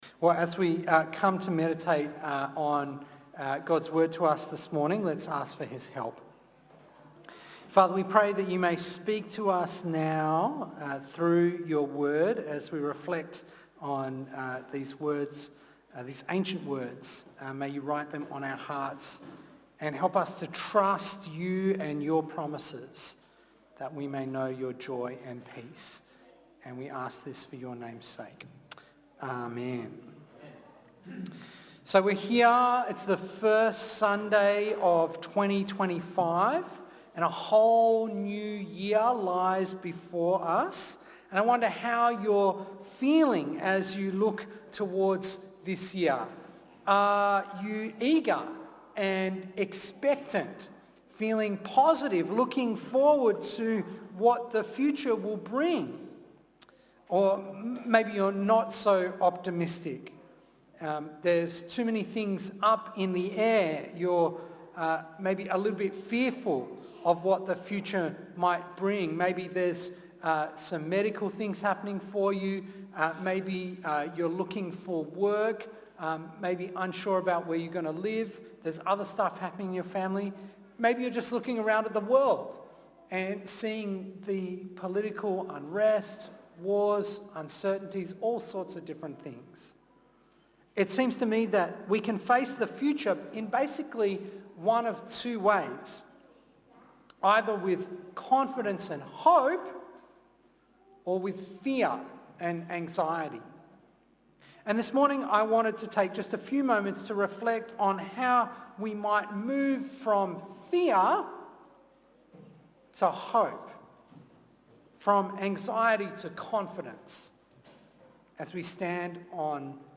2025 Facing the Future Preacher